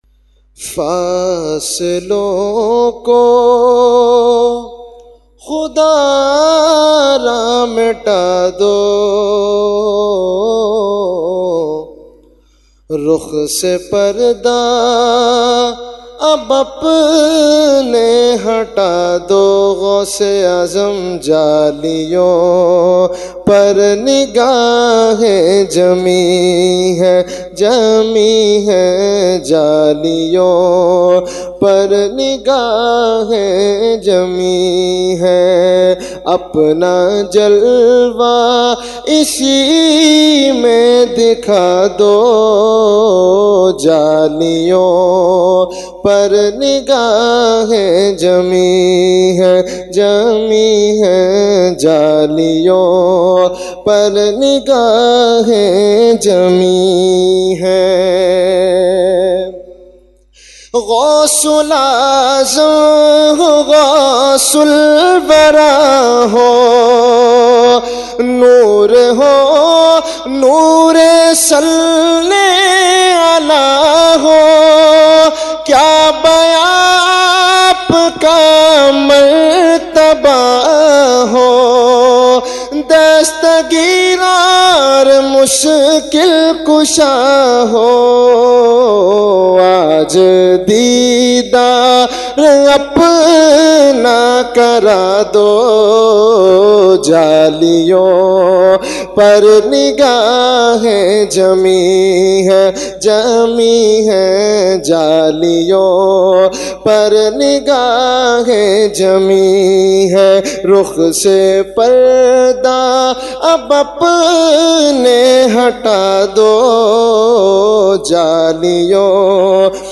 Category : Manqabat | Language : UrduEvent : 11veen Shareef 2019